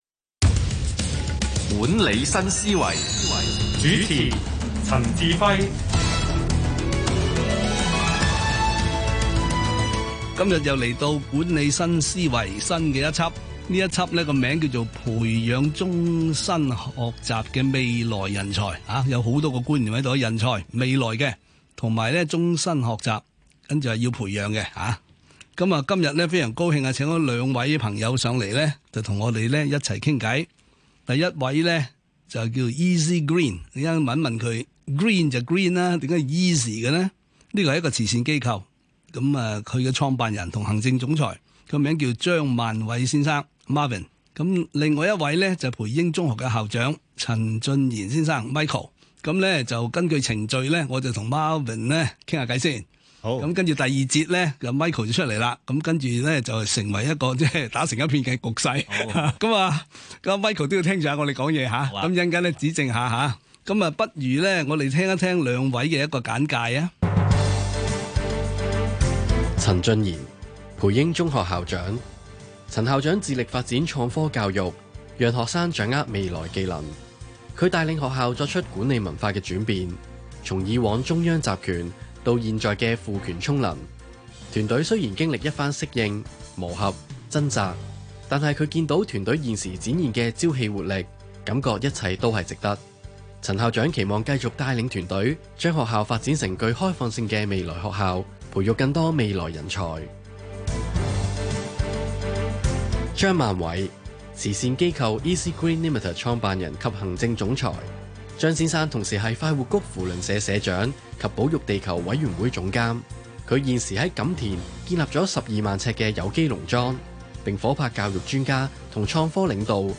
足本訪問重溫